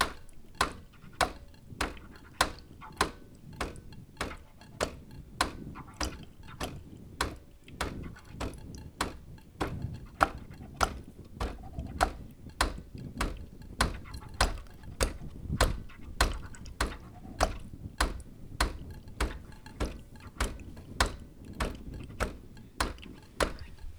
• cleaning and descaling for espresso machine.wav
Recorded on a Bosch Tassimo while the descaling program is running, with a Tascam DR 40.
cleaning_and_descaling_for_espresso_machine_EJK.wav